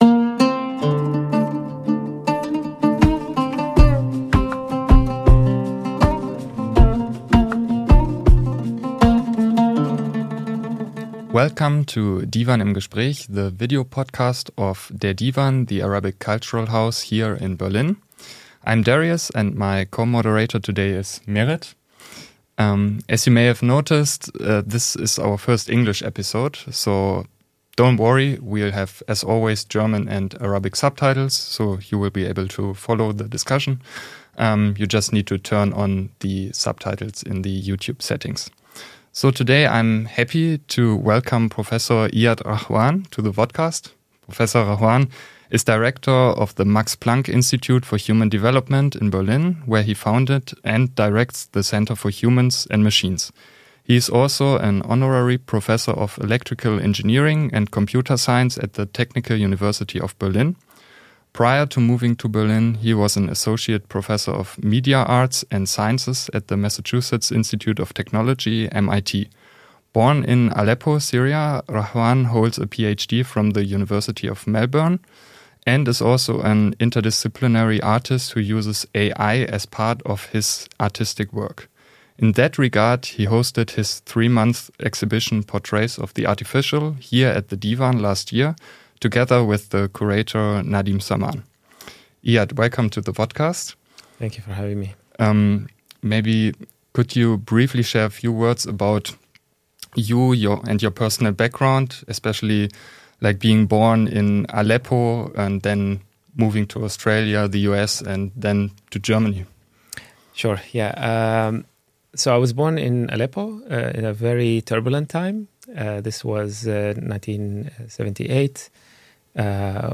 [EN] Im Gespräch mit Prof. Dr. Iyad Rahwan حوار الديوان مع البروفسور د. إياد رهوان ~ DIVAN Podcasts Podcast